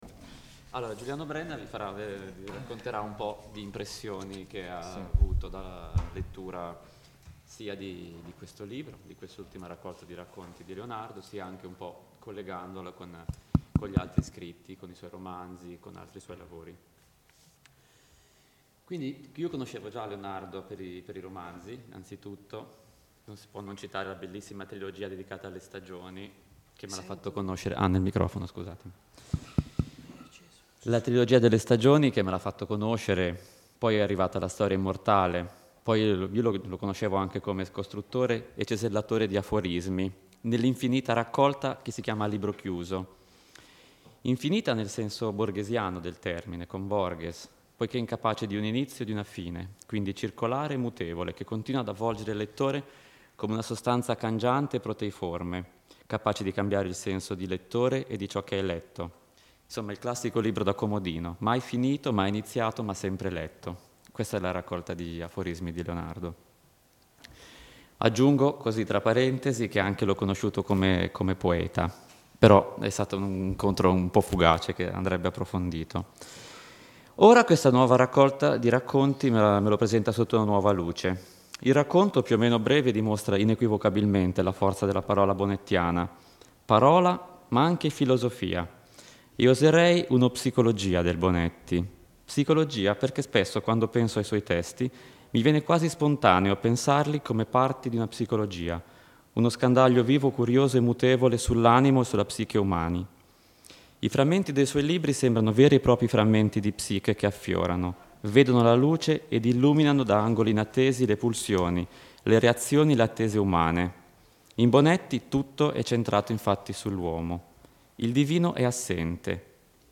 Discorso tenuto in occasione della presentazione del libro, Roma, 8 aprile 2015